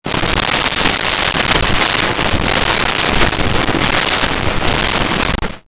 Drying Hands 1
drying-hands-1.wav